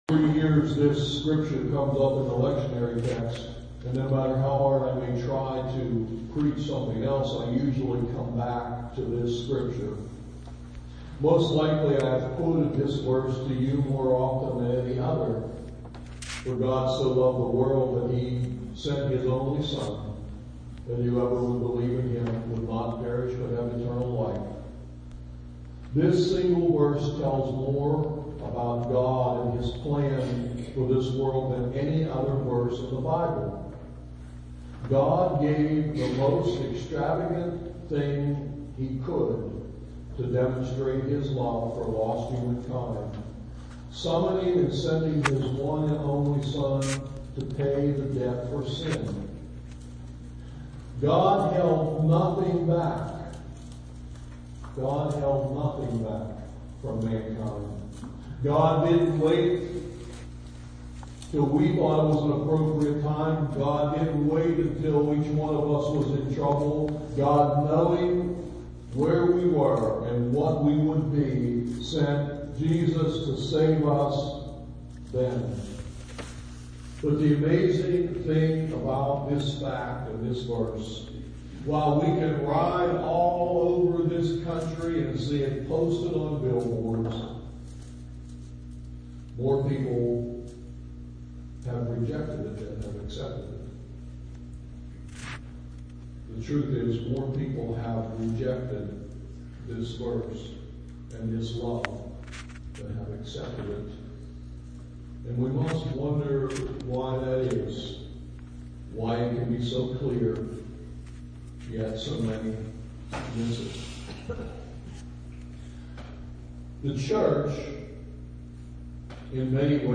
SERMON TEXT: John 3:1-17